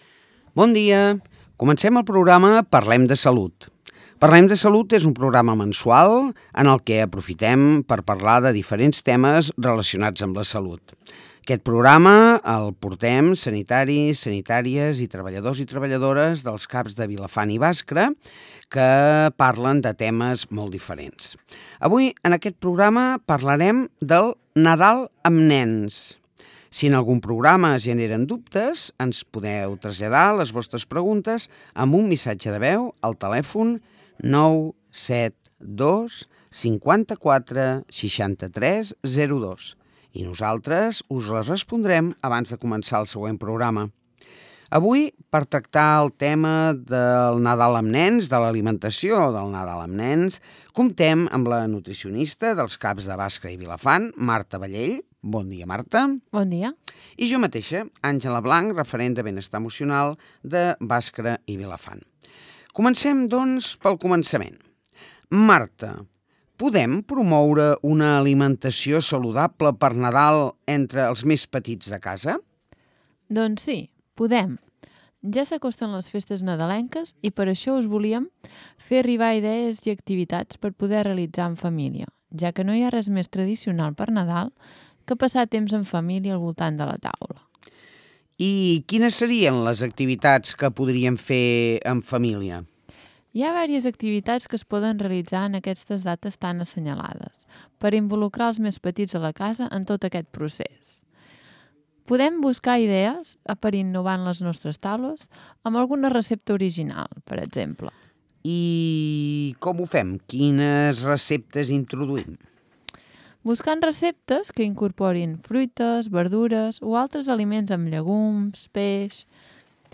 Avui a Les Veus del Matí hem compartit una nova edició de l’espai de Salut, amb professionals del CAP de Vilafant i Bàscara, per parlar d’un tema molt present en aquesta època: com afrontar el Nadal amb nens de manera saludable, serena i equilibrada.